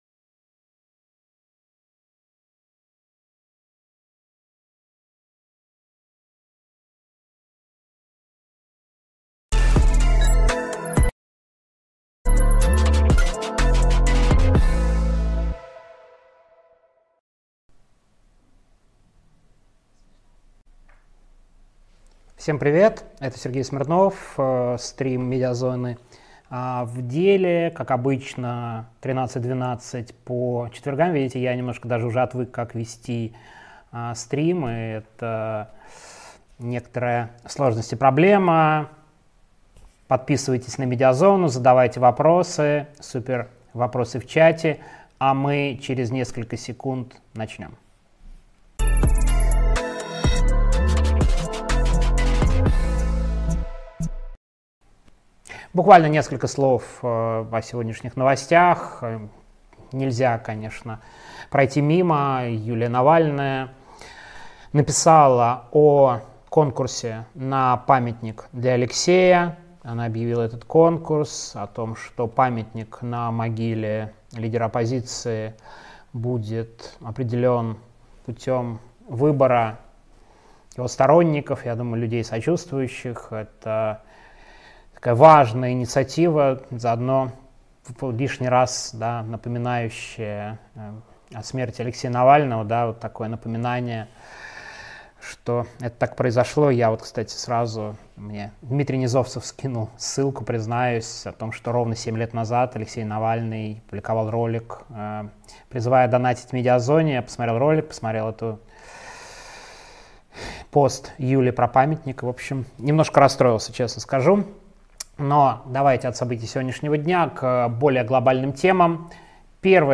Стрим